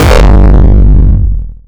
Index of /breakcore is not a good way to get laid/earthquake kicks 2
angry kick.wav